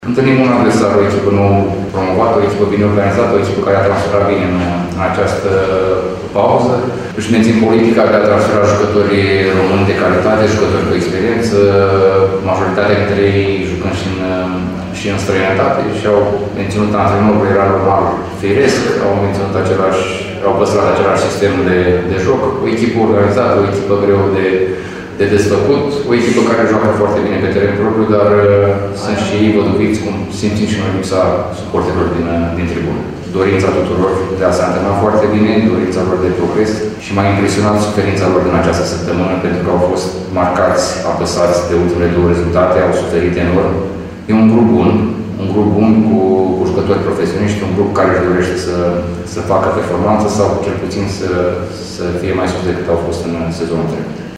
Meciul din această seară, de pe Francisc Neuman, a fost prefațat și de tehnicianul ieșean, Daniel Pancu: